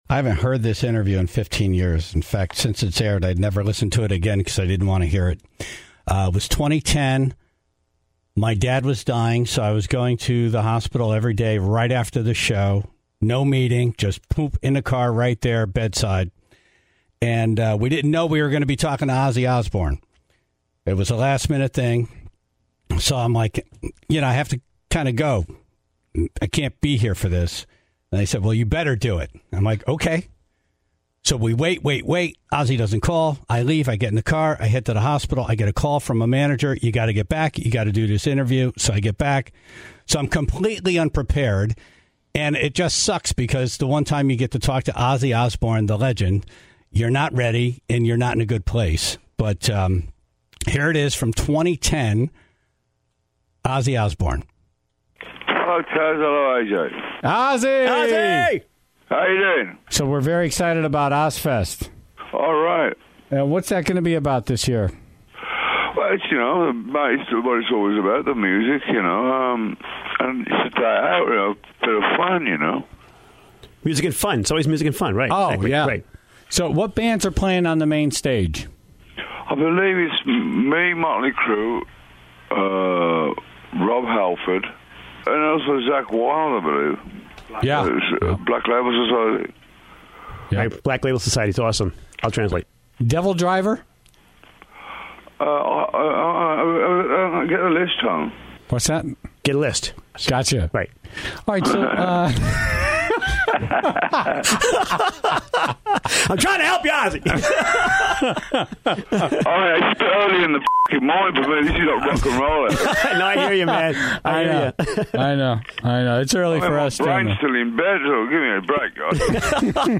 The call only aired a few times, but was revisited this morning to celebrate the life of the Prince of Darkness, and the answers that may never be fully understood.